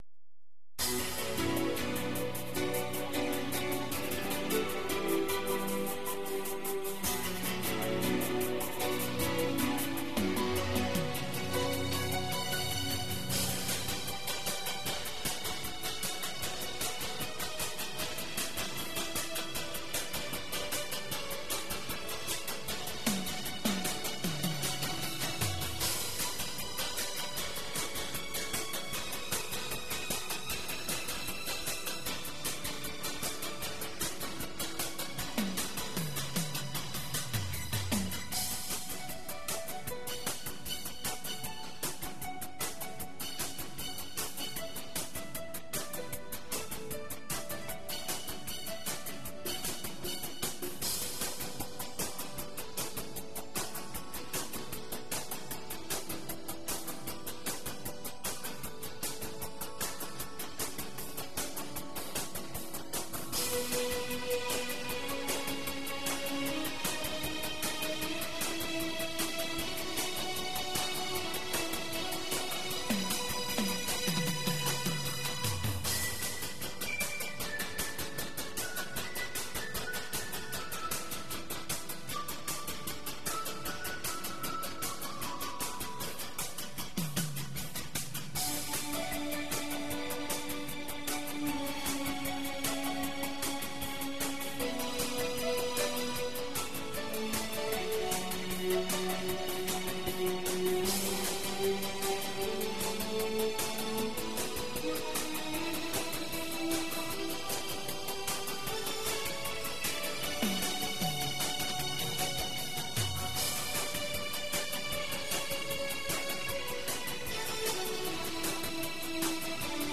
татарская музыка